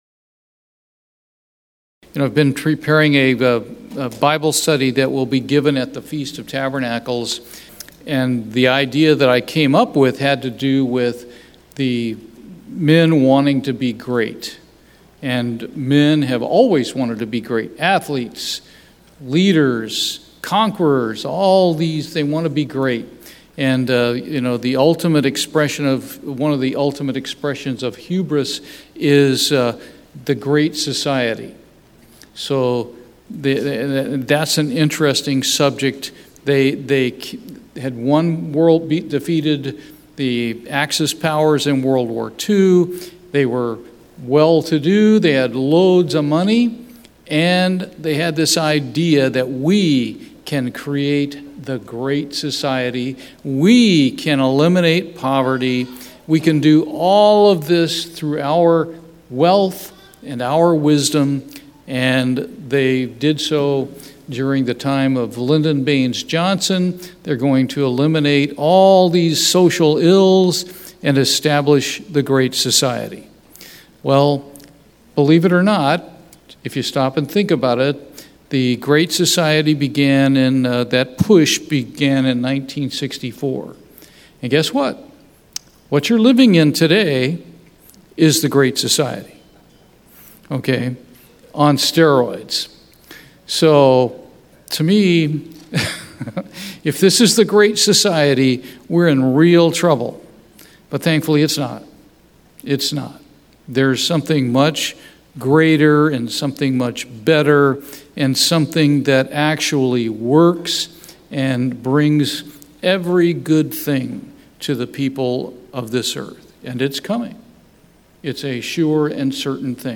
Bible Study, The Importance of Heb 13:8